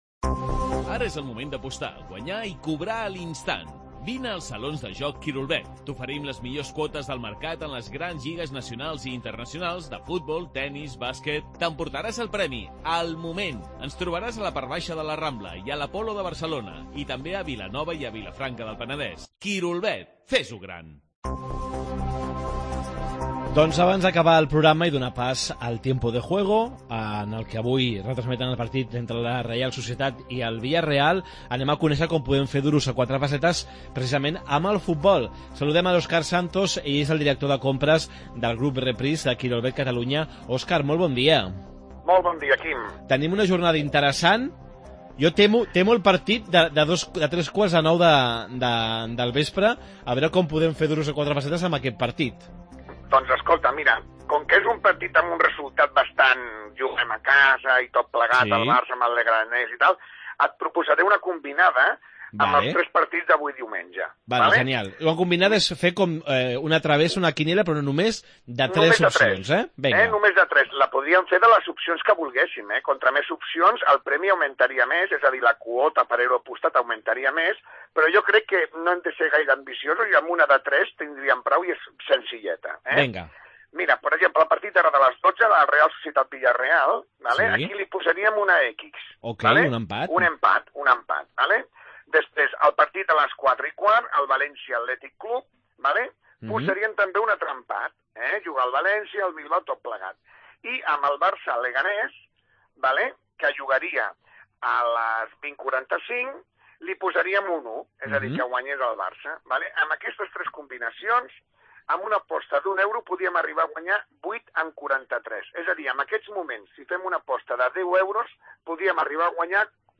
Com fer Duros a quatre pessetes amb la Lliga de futbol? Entrevista